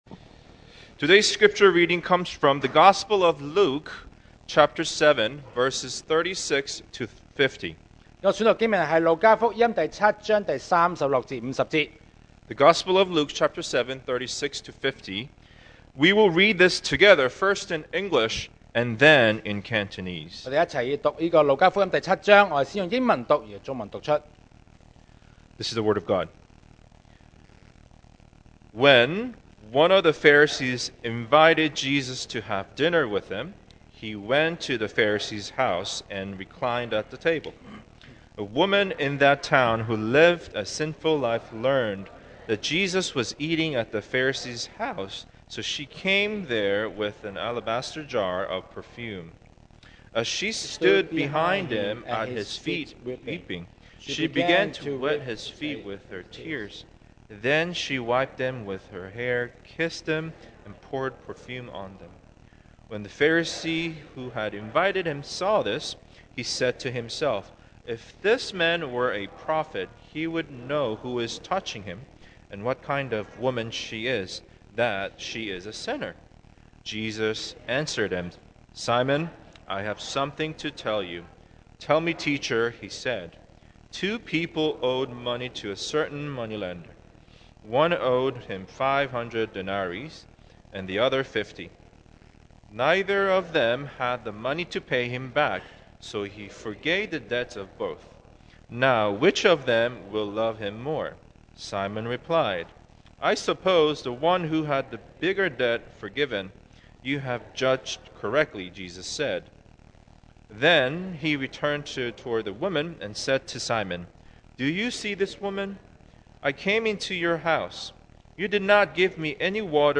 2024 sermon audios 2024年講道重溫 Passage: Luke 7:36-50 Service Type: Sunday Morning What Is Your Foundation?